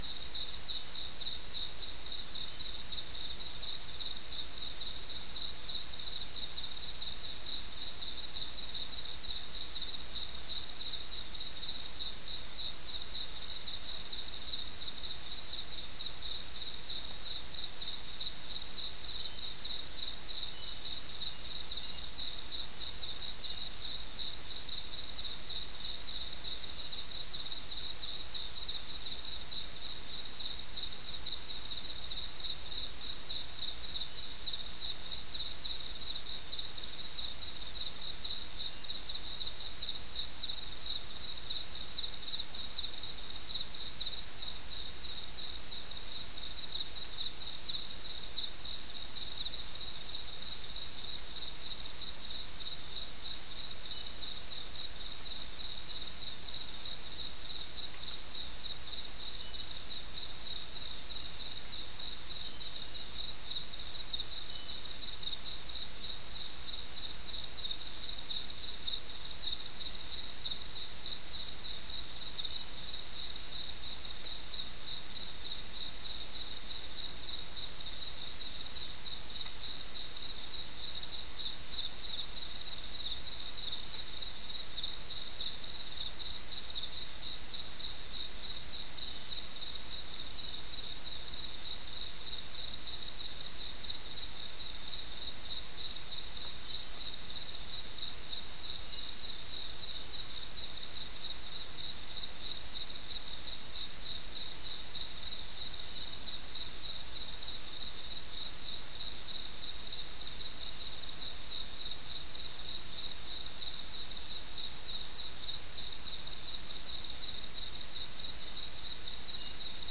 Soundscape Recording Location: South America: Guyana: Mill Site: 3